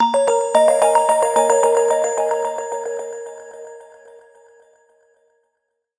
çoğunlukla eğlenceli ve hareketli zil seslerine sahip.